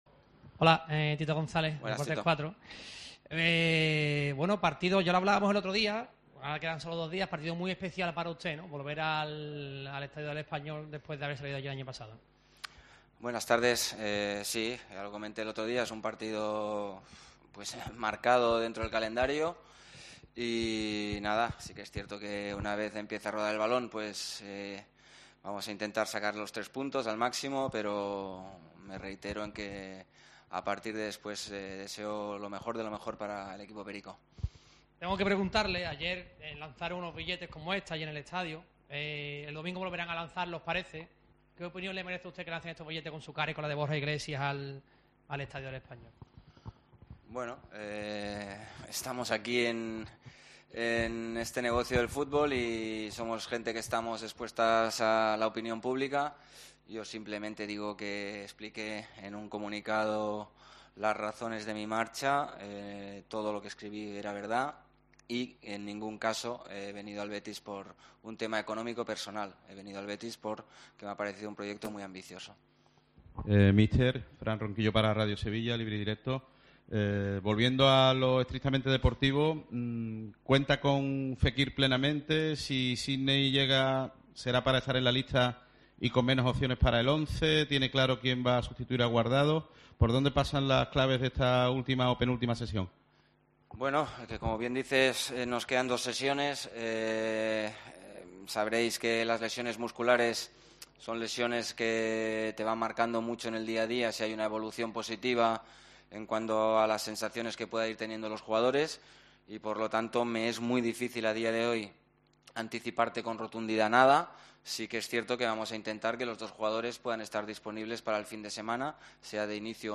Rubi, en la rueda de prensa previa al partido con el Español